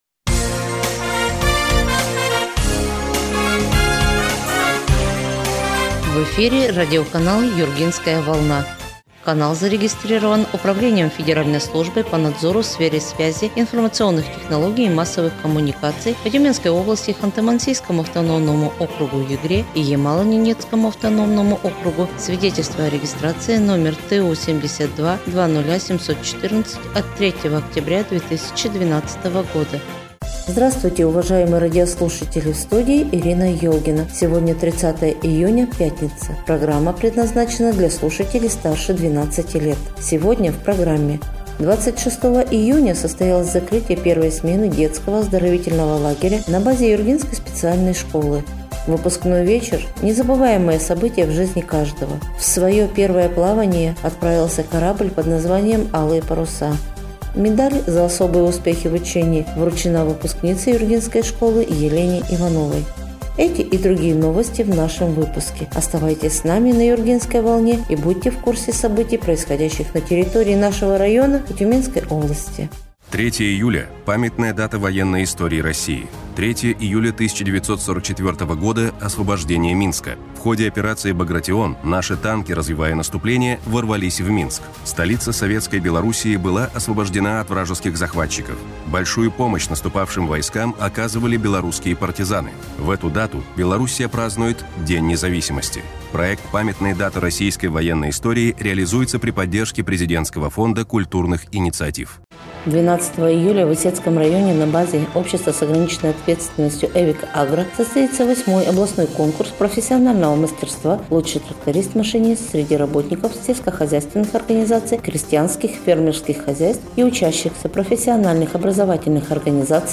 Эфир радиопрограммы "Юргинская волна" от 30 июня 2023 года